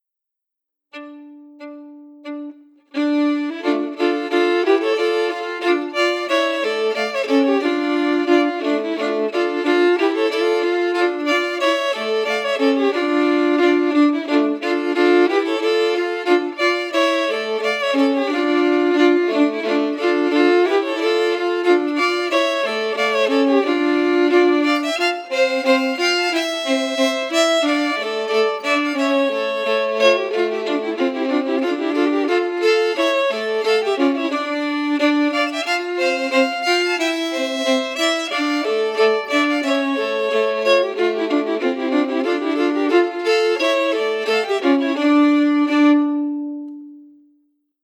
Key: Dm*
Harmony emphasis
R:Reel (8x40) ABABB
Region: Scotland